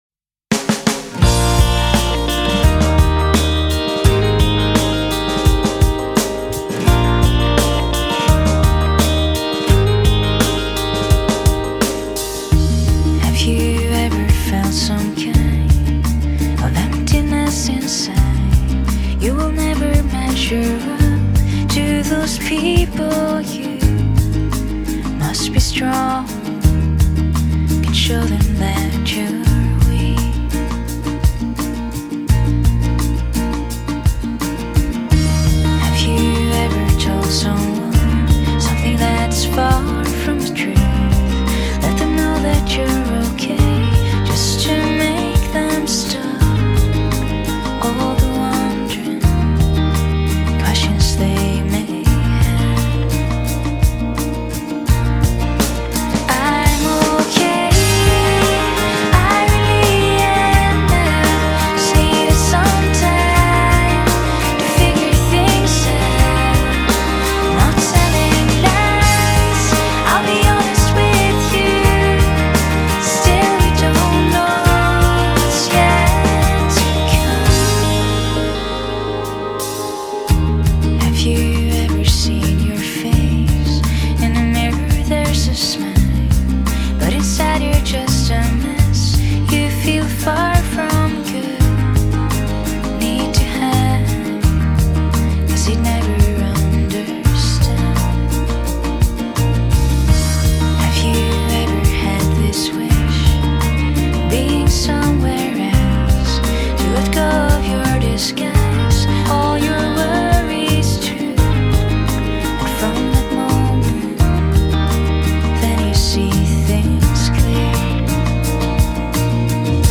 主唱美声献唱